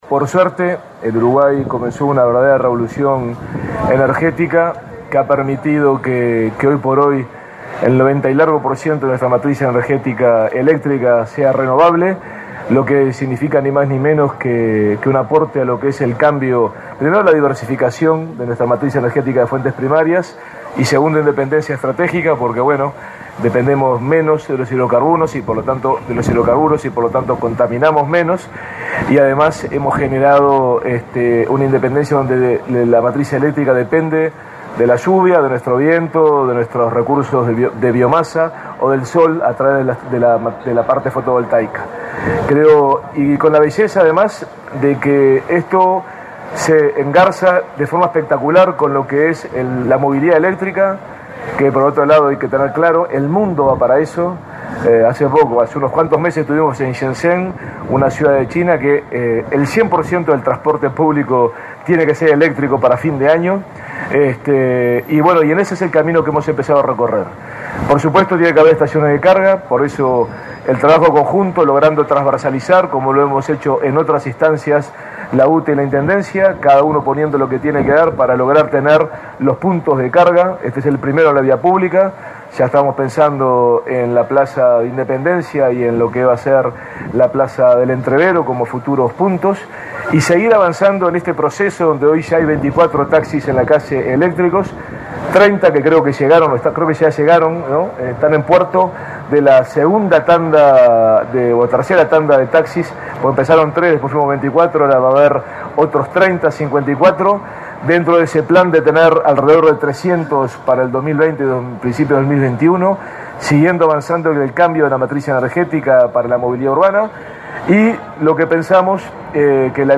“Uruguay comenzó una verdadera revolución energética que ha permitido que hoy más del 90% de la matriz energética sea renovable”, recordó el intendente de Montevideo, Daniel Martínez, en el evento por la instalación de un centro de recarga de autos eléctricos de UTE en el centro de Montevideo. Destacó la independencia de los hidrocarburos que genera este nuevo sistema y el cuidado del medio ambiente.